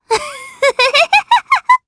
Viska-Vox-Laugh_jp.wav